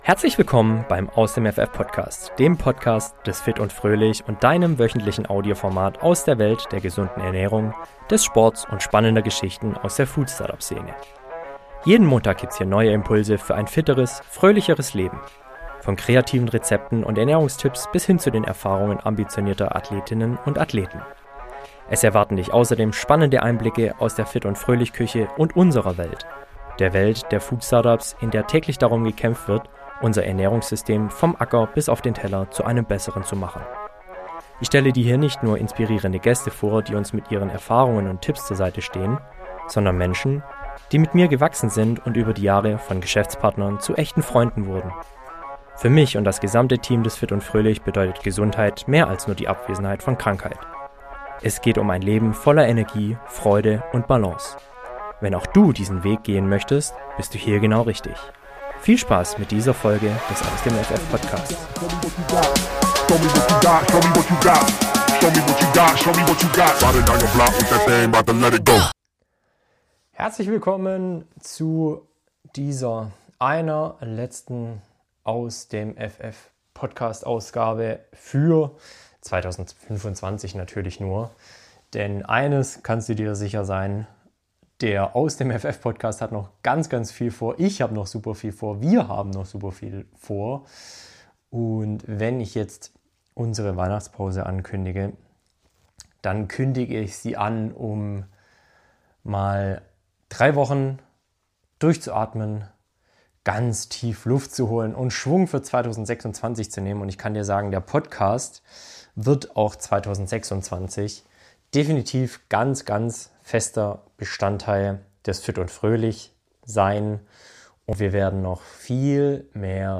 Ich teile, was uns als Startup bewegt hat, wie wir als Team zusammengewachsen sind und warum Gesundheit für uns mehr als nur Fitness bedeutet. Außerdem erwarten Dich exklusive Stimmen und Ernährungstipps direkt vom Business-Christmas-Event auf dem Rooftop – mit Gästen aus der Food- und Startup-Szene.